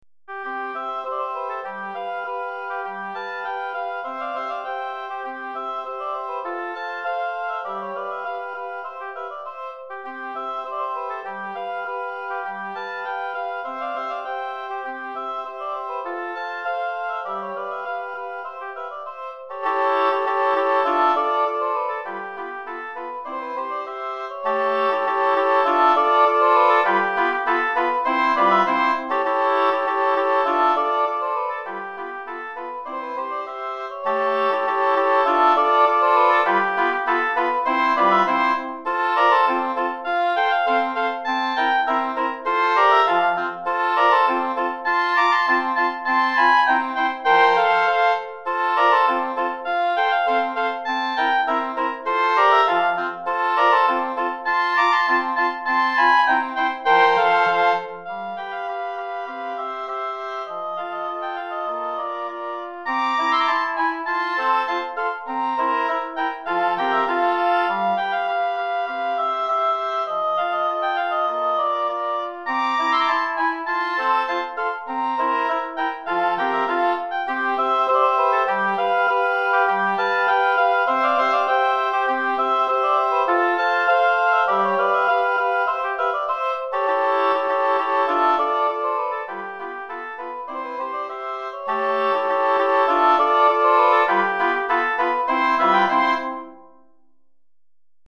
3 Hautbois et Cor Anglais